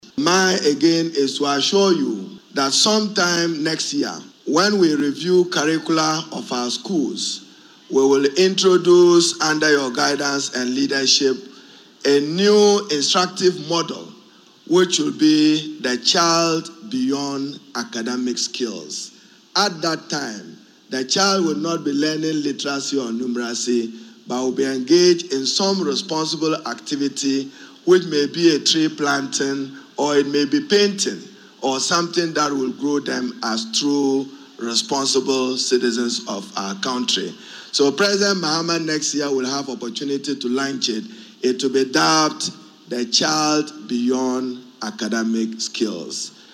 Also at the event, Minister for Education, Haruna Iddrisu, revealed that a new school model focused on shaping responsible citizens will be rolled out next year.